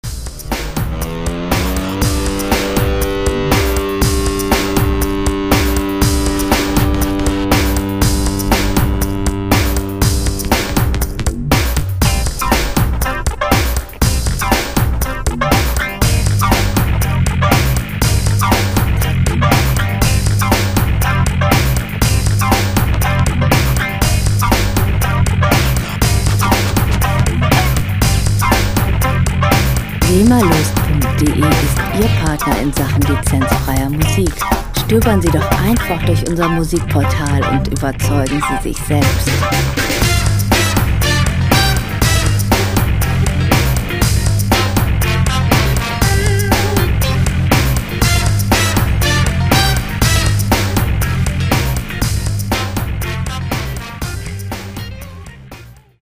Rockmusik - Legenden
Musikstil: Funk Rock
Tempo: 120 bpm
Tonart: G-Moll
Charakter: kraftvoll, energisch
Instrumentierung: E-Gitarre, Bläser, Schlagzeug, Posaune